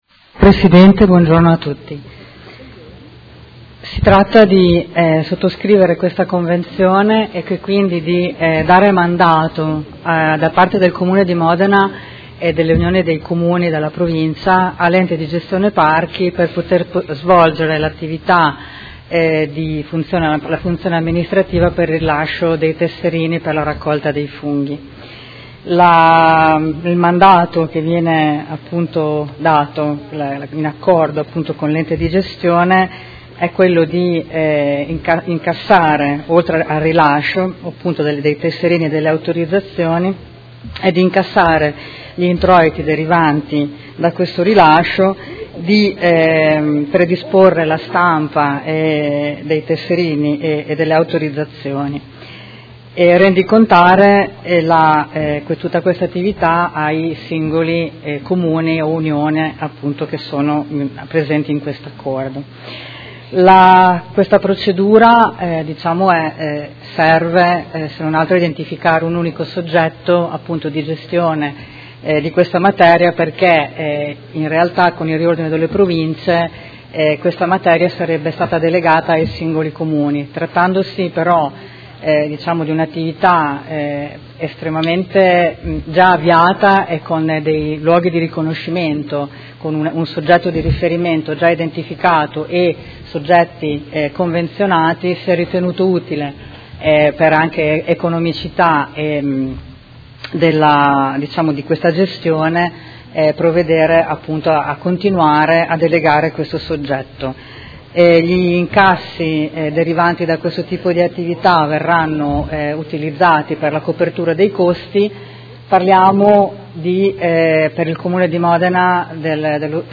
Seduta del 28/06/2018. Proposta di deliberazione: Adesione alla proposta di convenzione per l’esercizio delle funzioni amministrative in materia di raccolta dei funghi epigei spontanei fra l’Ente di gestione parchi Emilia Centrale, l’Unione dei Comuni modenesi dell’area Nord, l’Unione delle Terre d’Argine, l’Unione dei Comuni del Sorbara, l’Unione dei Comuni del Distretto ceramico, l’Unione dei Comuni Terre dei Castelli, il Comune di Modena – Approvazione schema di convenzione e relative scelte operative